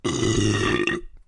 巨大而响亮的爆炸声
描述：一声巨响。小心你的耳鼓！
Tag: 有声 炸弹 拉泽 轰的一声 爆炸 导弹 火炮 激光 爆炸